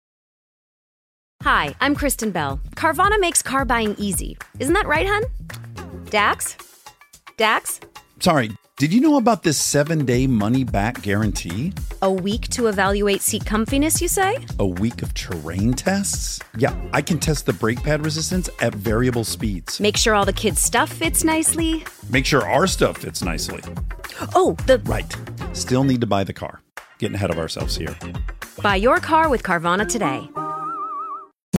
podcast ad for Carvana’s “7-day money-back guarantee,” celebrity power couple Kristen Bell and Dax Shepard offer up some ideas on the kinds of tests you can perform during the week-long trial period, from evaluating “seat comfiness” (Kristen) to assessing “brake pad resistance at variable speeds” (Dax).
Carvana-7-day-money-back-guarantee-ad.mp3